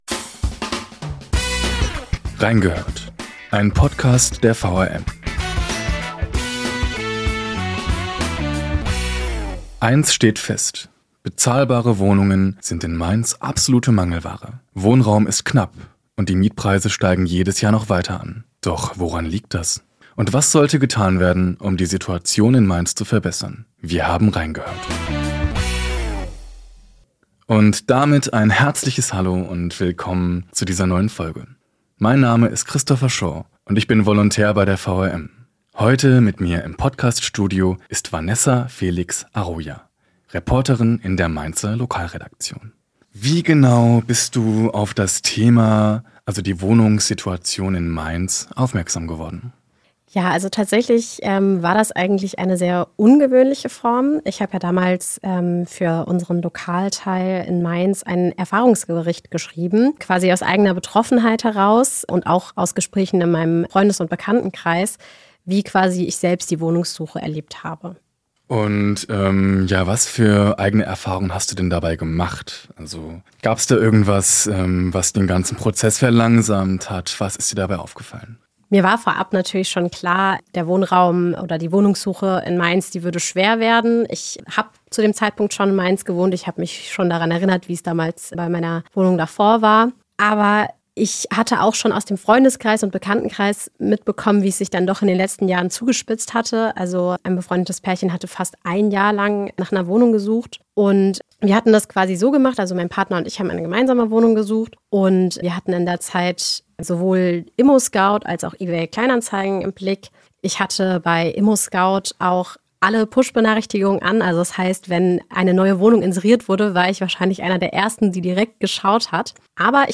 Junge Journalisten blicken gemeinsam mit erfahrenen Reportern auf aktuelle Themen und bewegende Geschichten aus Wiesbaden, Mainz und Darmstadt. Gemeinsam erzählen sie, wie die Geschichten entstehen, die sie für den Wiesbadener Kurier, die Allgemeine Zeitung und das Darmstädter Echo recherchieren.